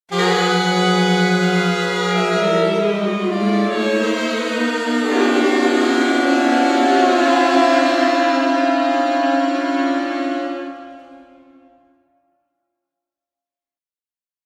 Filmmusikschaffende dürfen sich über ein breites Angebot am Effektklängen freuen. Neben diversen Clustern …